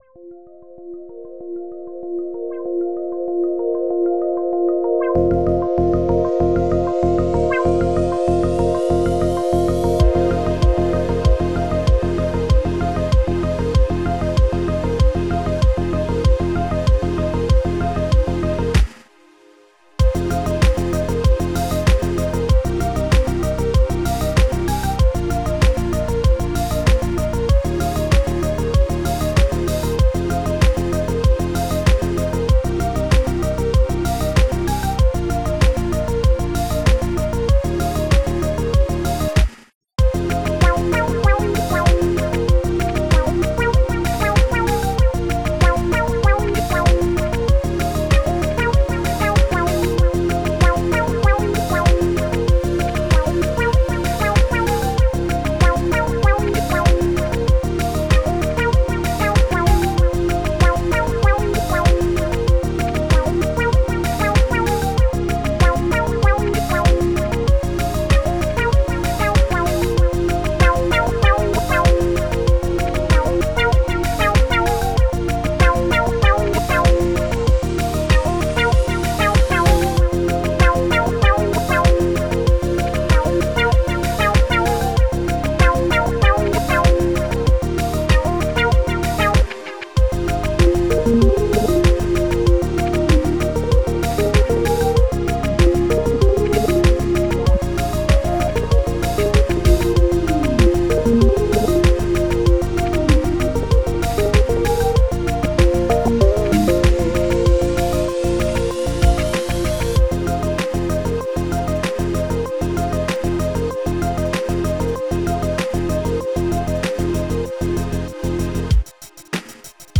Style: Synthwave